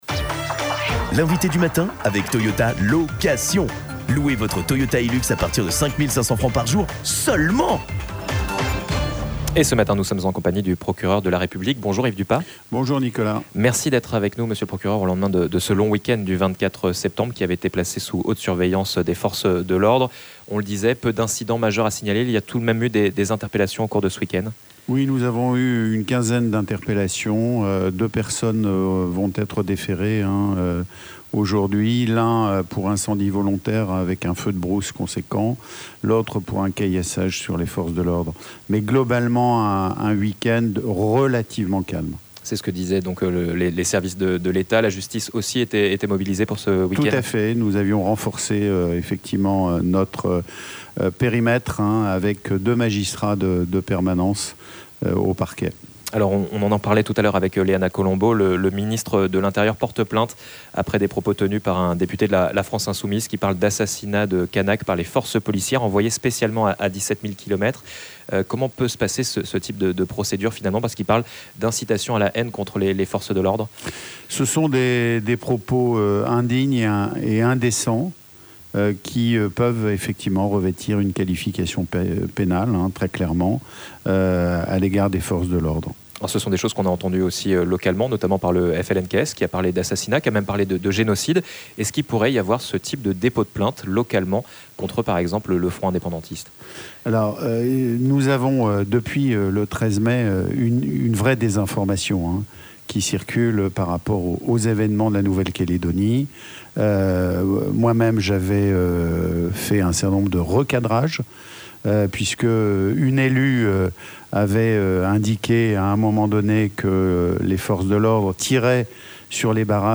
L'INVITE DU MATIN : YVES DUPAS
Le point, ce matin, sur les différentes opérations judiciaires en cours et sur l'évolution de la situation depuis le 13 mai, en compagnie du Procureur de la République, Yves Dupas.